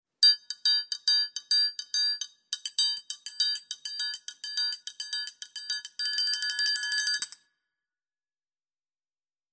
En las actividades presentamos una rima sobre las frutas para vivenciar el ritmo y Azulino nos acompaña tocando en triángulo.
triangulo_mp3.mp3